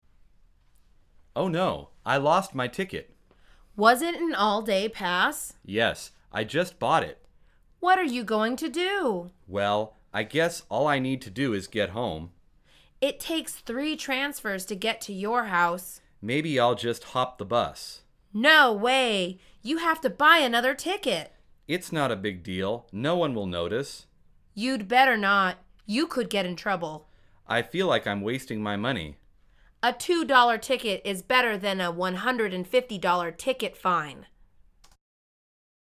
مجموعه مکالمات ساده و آسان انگلیسی – درس شماره بیست و دوم از فصل سوار شدن به اتوبوس: گم کردن بلیت